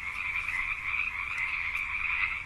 Frogs_02.ogg